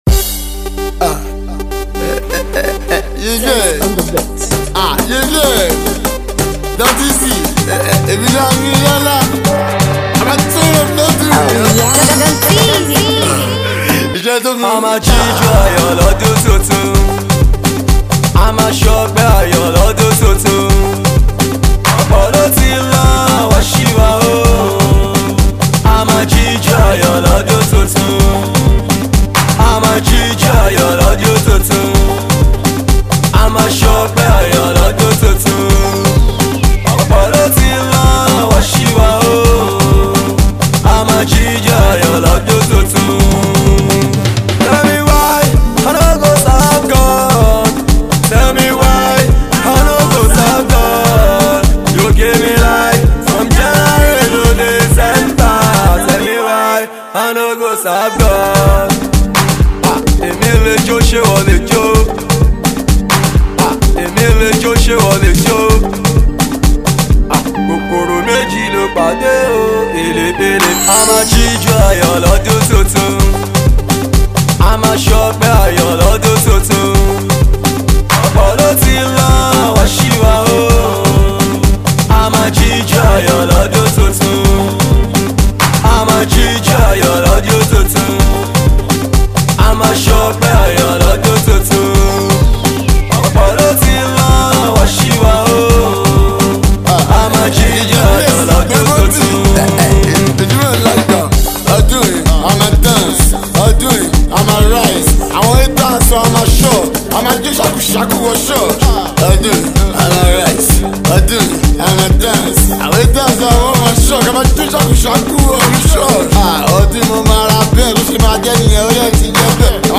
ghopel dance hall song